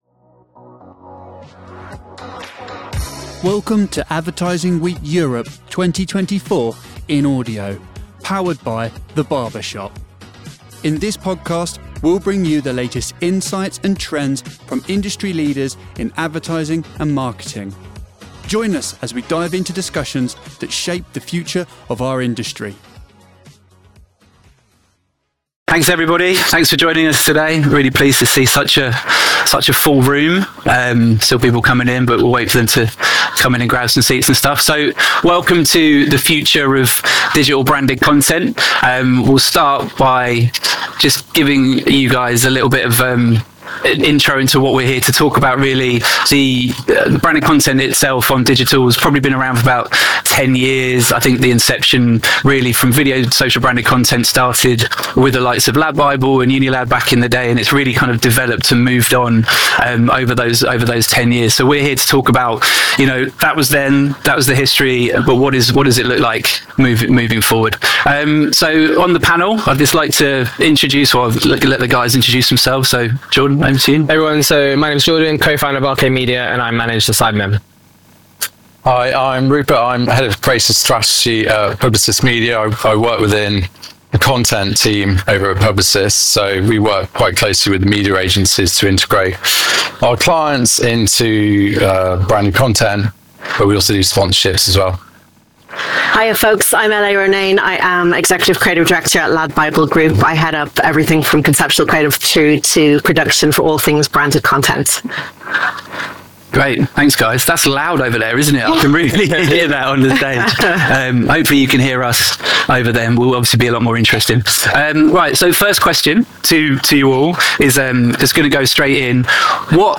Quickly and easily listen to Advertising Week Europe 2024 in Audio for free!